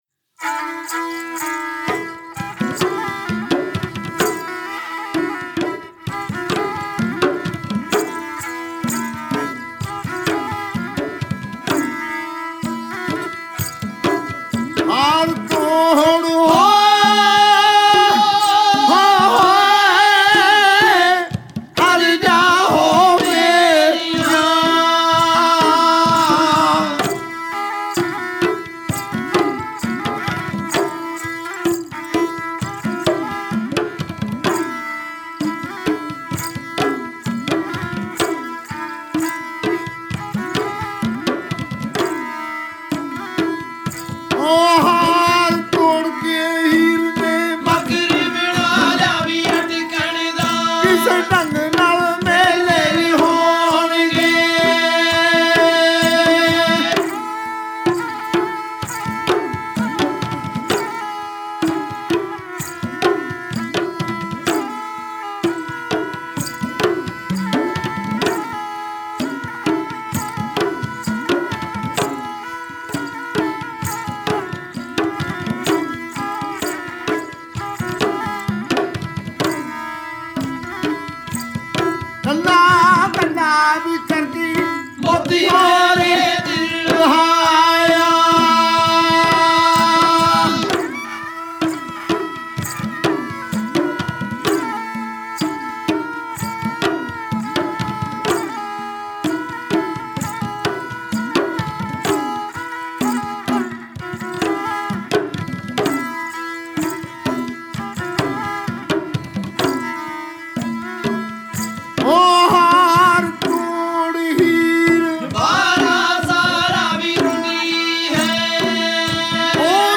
Kalaam/Poetry, Punjabi